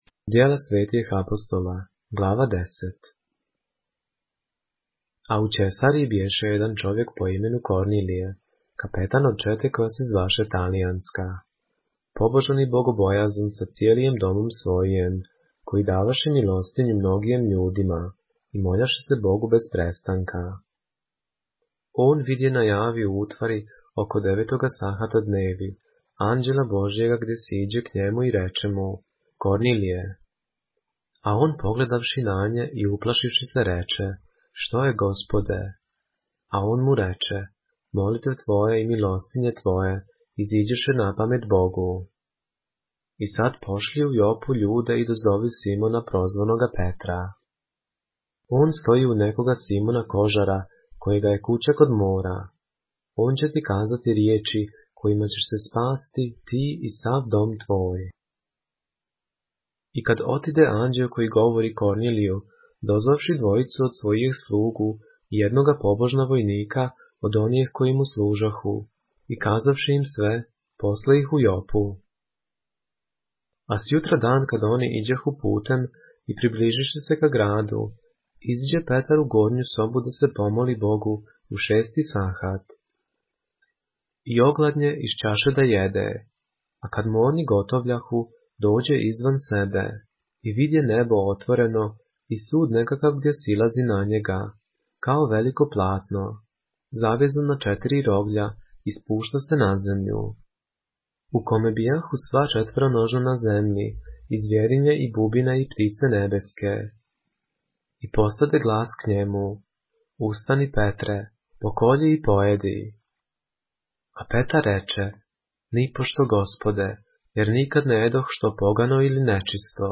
поглавље српске Библије - са аудио нарације - Acts, chapter 10 of the Holy Bible in the Serbian language